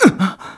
SSBB_Link_Hurt1.wav